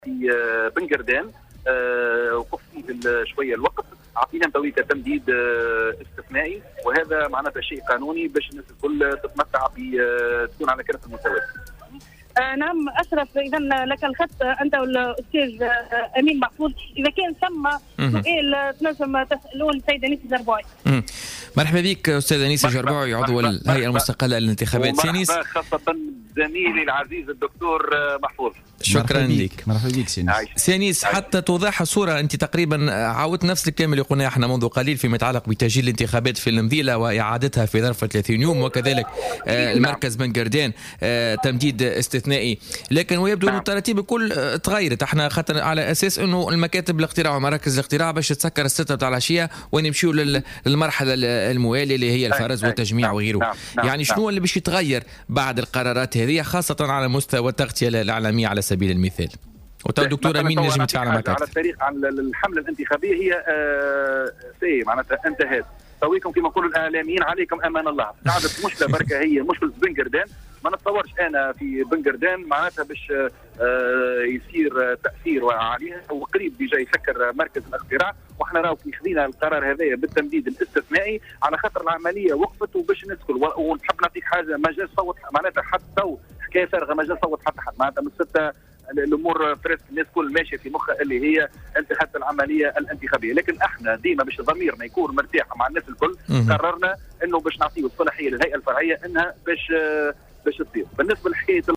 Dans une déclaration accordée à Jawhara FM ce dimanche 6 mai 2018, le membre de l'ISIE Anis Jarboui a annoncé qu'il a été décidé de maintenir le bureau de vote Al Mouthabara à Ben Guerdane ouvert exceptionnellement pour une heure supplémentaire.